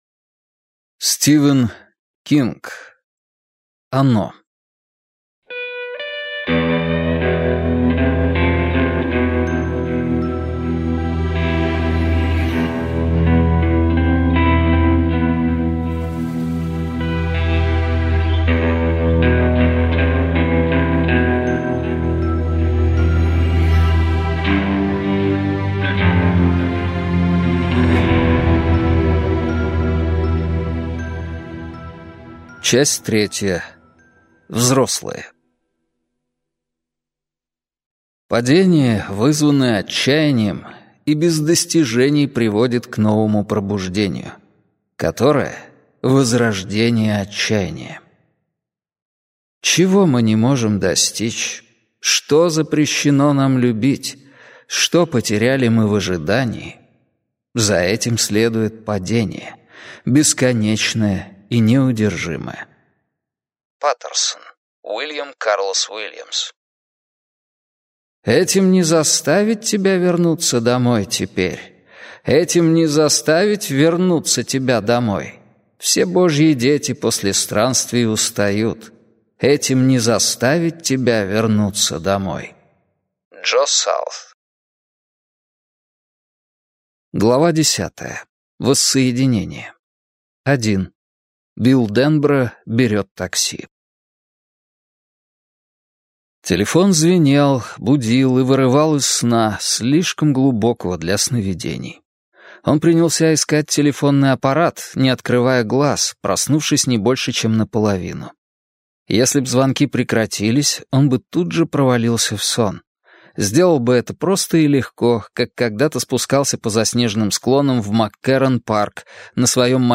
Аудиокнига Оно. Том 2. Воссоединение - купить, скачать и слушать онлайн | КнигоПоиск